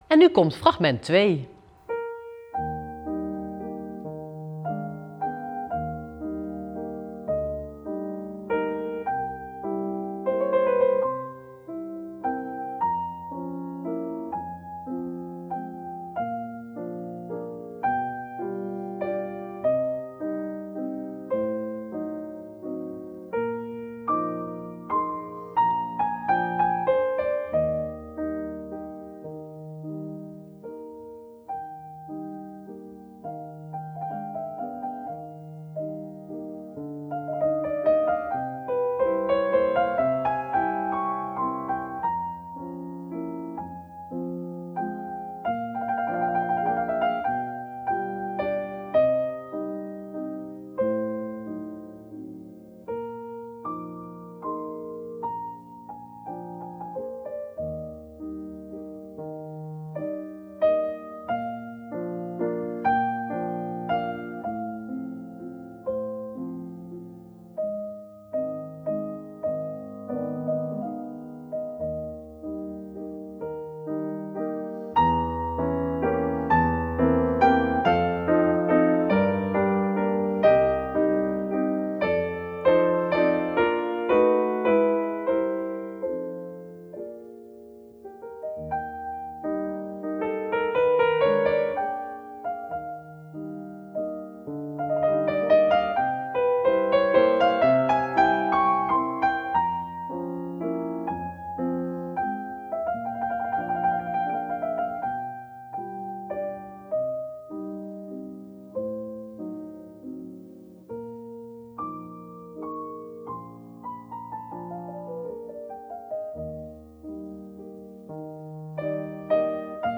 Audio 2.7 t/m 2.9 Verschillende stijlen muziek om op te dansen.
2.8 Alfabet klassieke pianomuziek - 4:31 minuten -